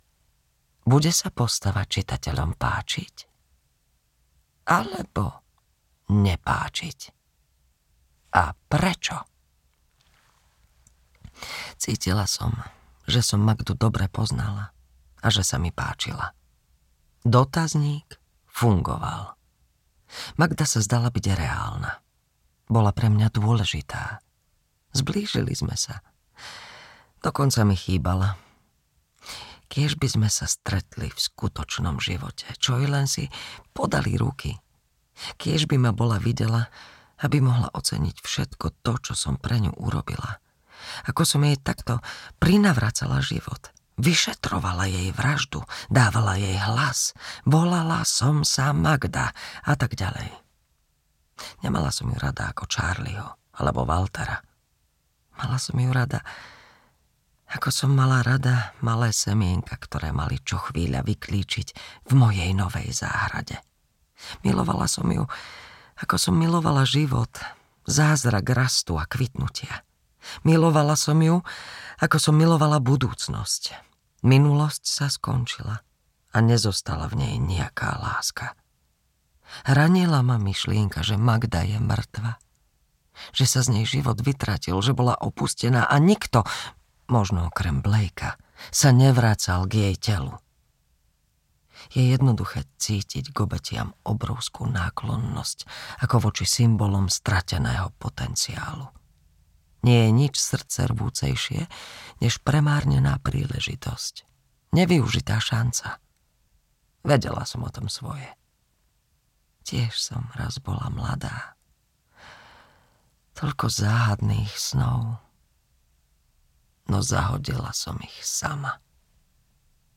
Smrť v jej rukách audiokniha
Ukázka z knihy